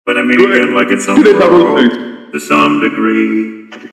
Play, download and share RightButImEeeEeANAgaaiaain original sound button!!!!
autotuneright.mp3